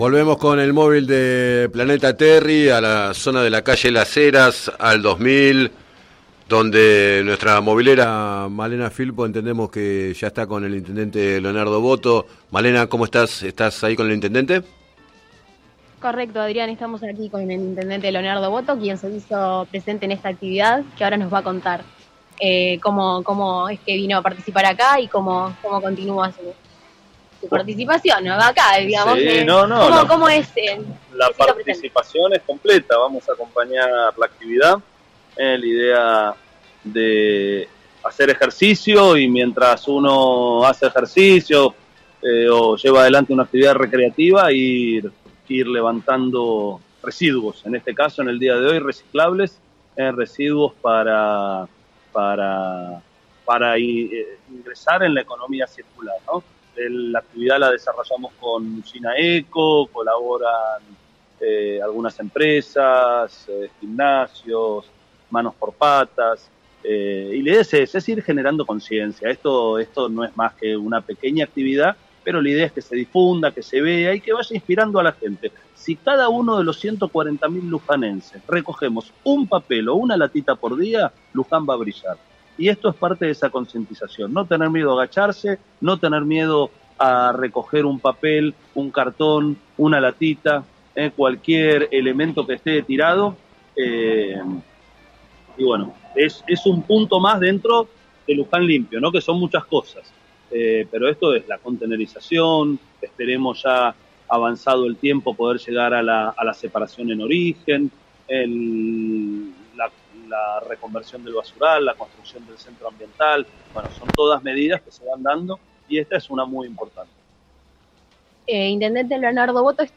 En declaraciones al programa Planeta Terri mientras se desarrollaba la jornada de “Plogging”, el jefe comunal explicó que la propuesta había sido organizada por Usina Eco y el Municipio, con el apoyo de otras organizaciones y empresas, para generar conciencia.